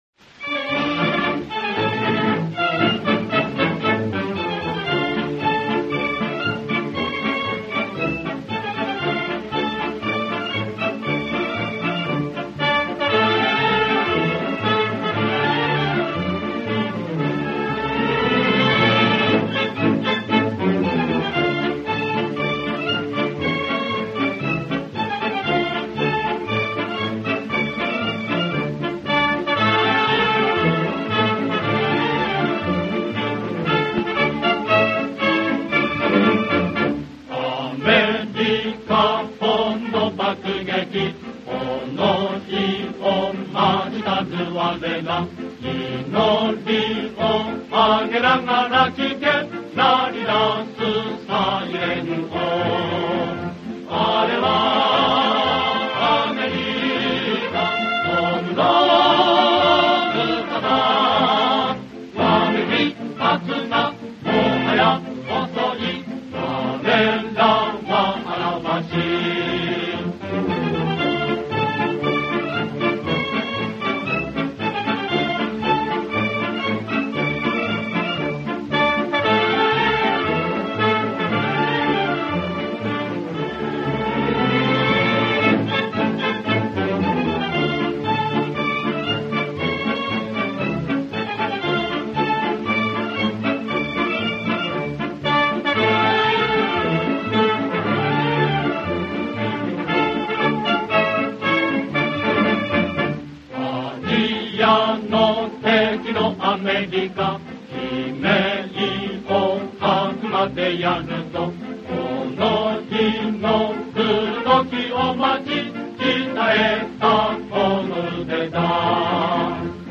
У японцев довольно красивая музыка (марши) времен войны.